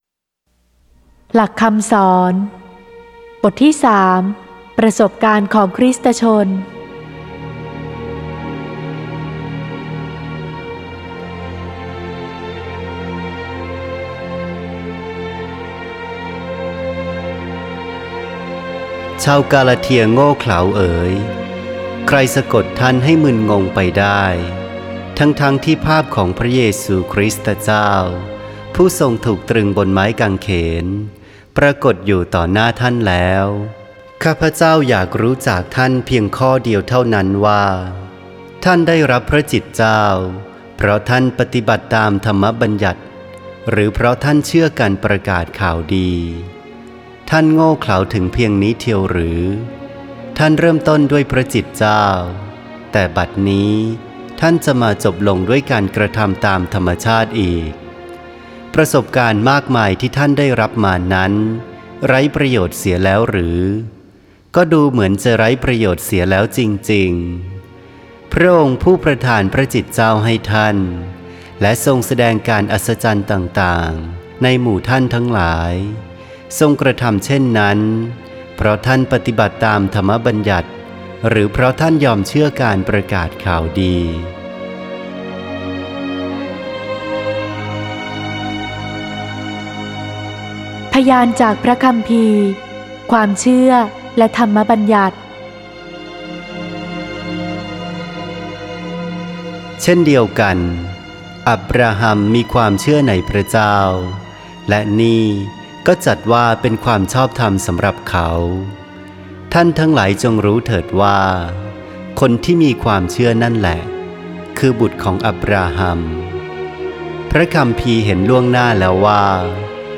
(ไฟล์ "เสียงวรสาร" โดย วัดแม่พระกุหลาบทิพย์ กรุงเทพฯ)